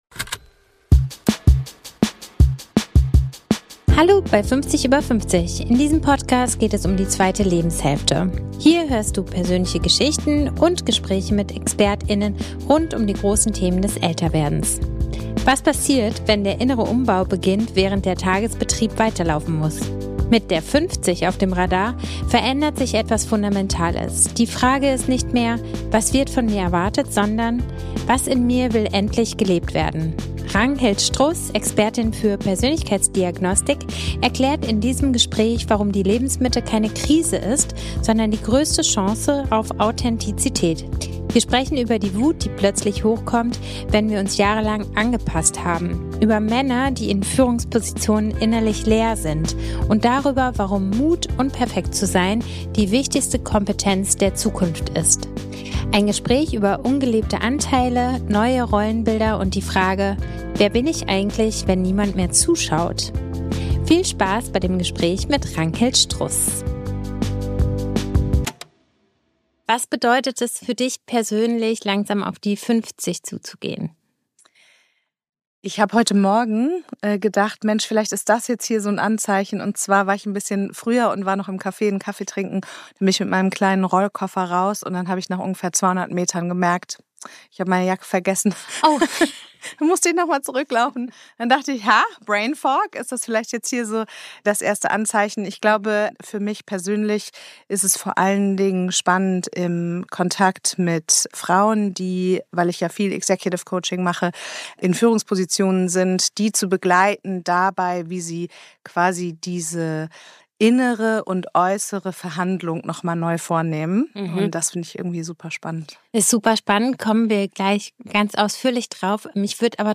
Und darüber, warum der Mut, unperfekt zu sein, die wichtigste Kompetenz der Zukunft ist. Ein Gespräch über ungelebte Anteile, neue Rollenbilder und die Frage: Wer bin ich eigentlich, wenn niemand mehr zuschaut?